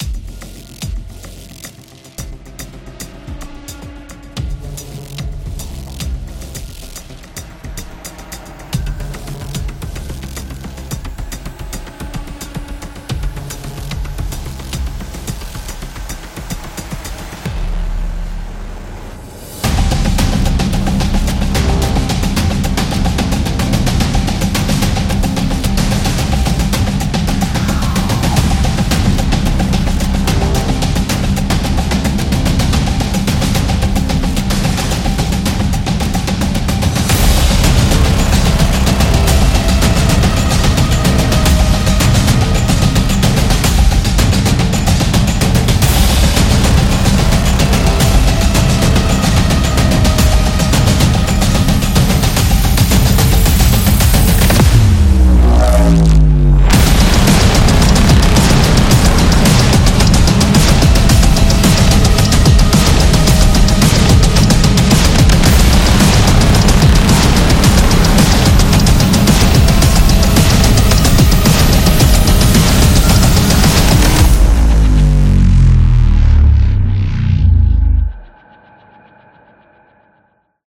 Cinematic